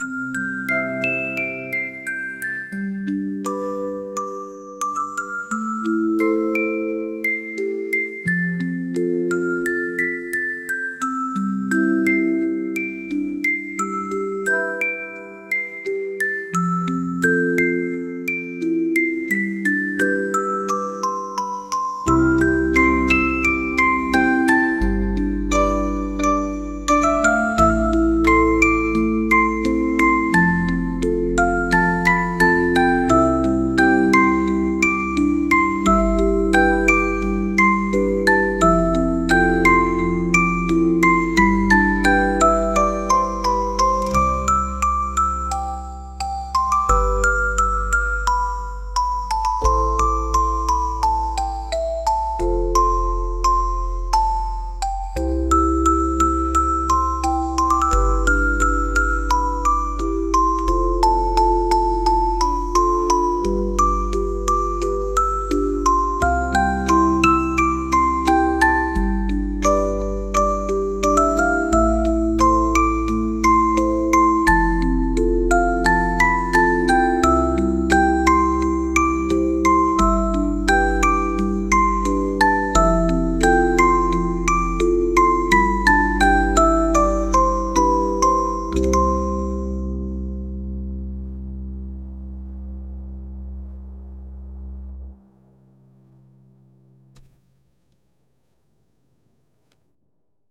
ゆったりしたオルゴール曲です。